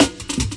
Instrument 00 is the kick and Instrument 01 is the snare.